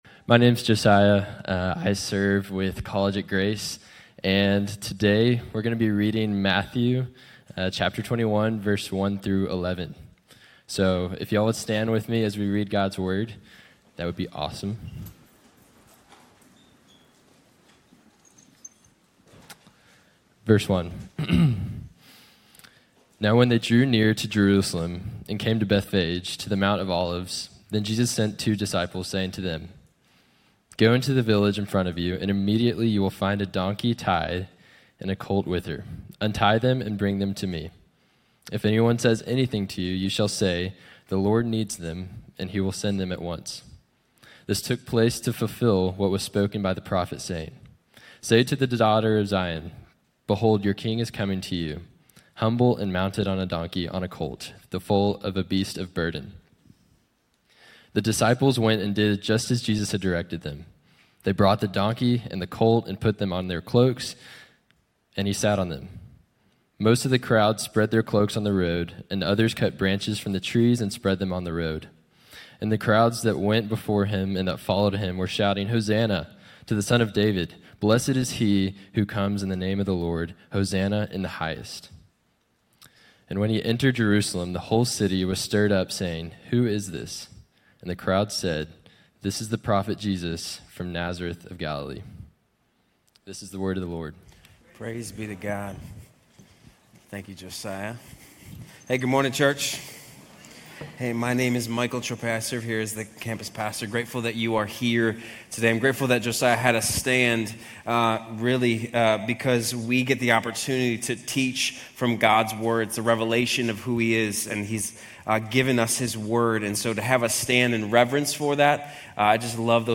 Grace Community Church University Blvd Campus Sermons 4_13 University Blvd Campus Apr 14 2025 | 00:33:55 1x 00:00 / 00:33:55 Subscribe Share RSS Feed Share Link Embed